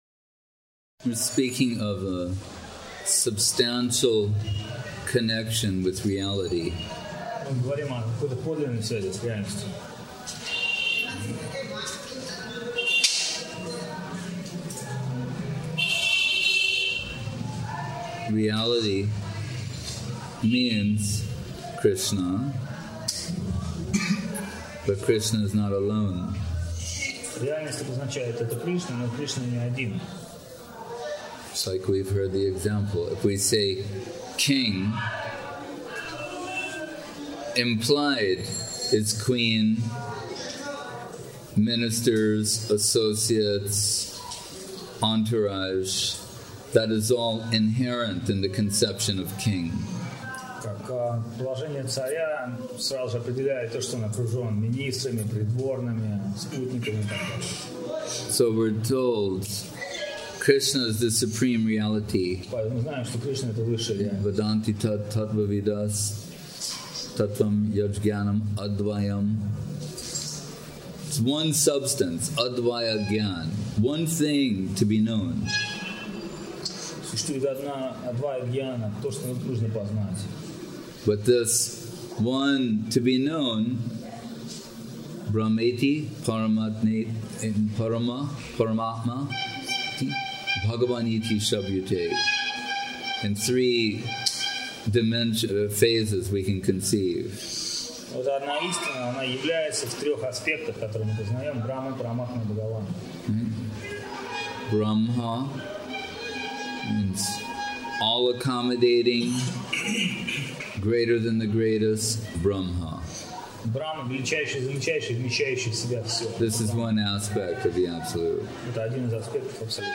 Place: SCSMath Nabadwip